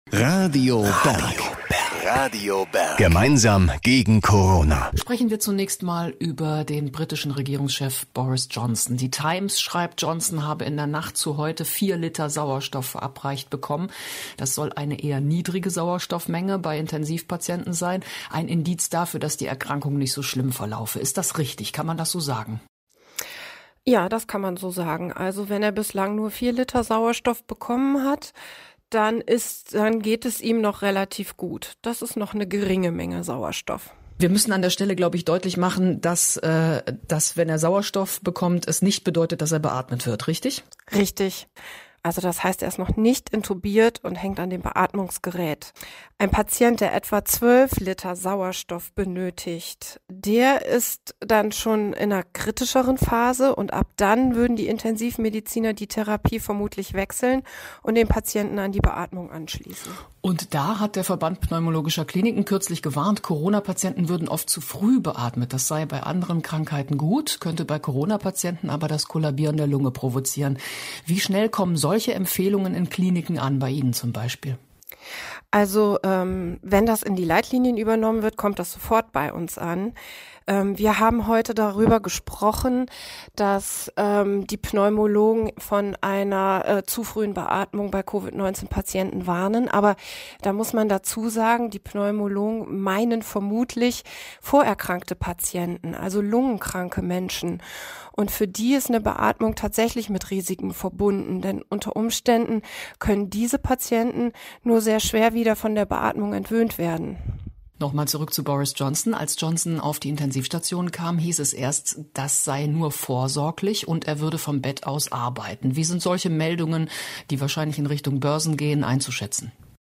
Die Interviews aus 2020